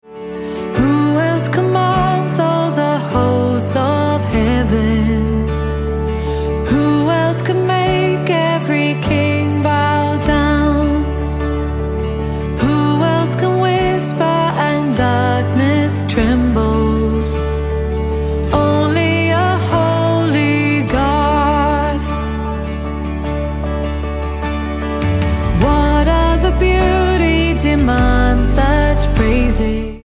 D